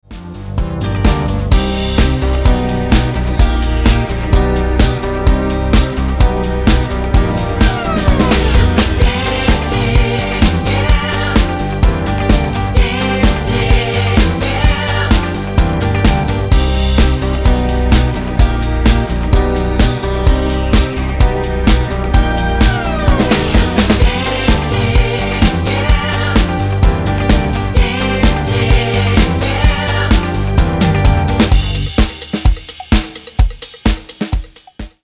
Tonart:Gm mit Chor